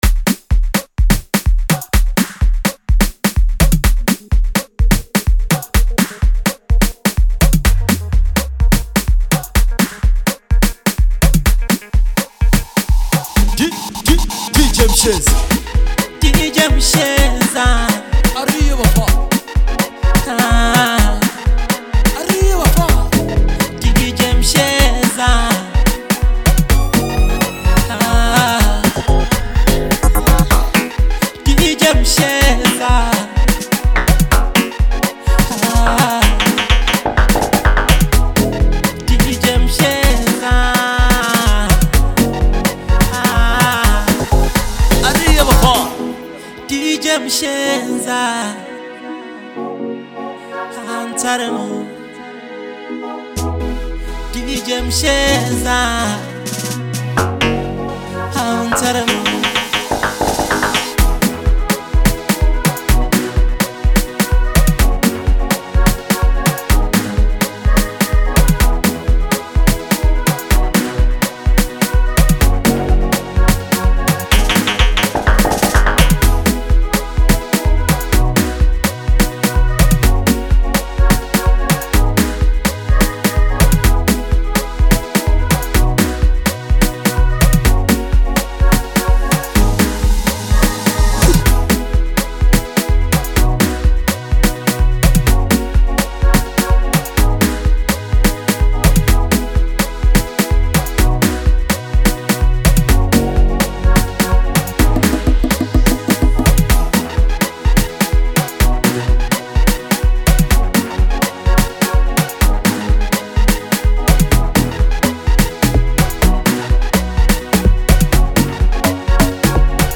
Genre: Lekompo / Bolo House